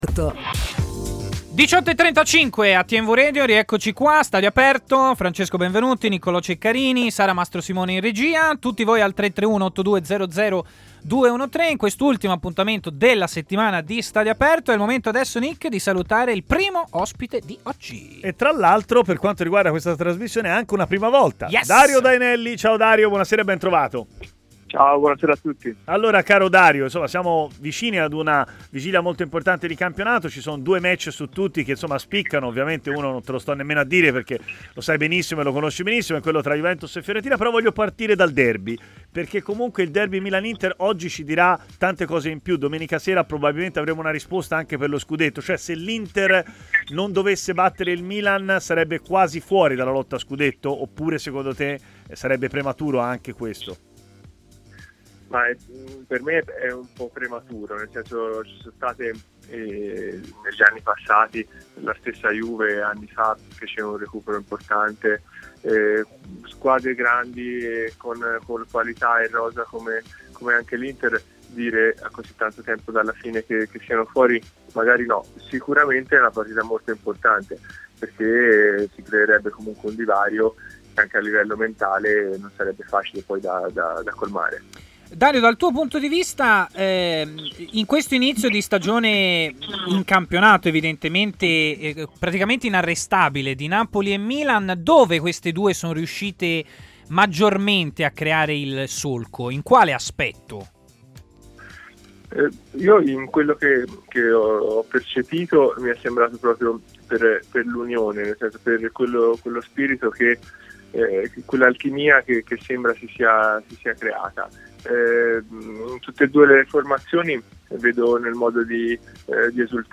ha così parlato in diretta a Stadio Aperto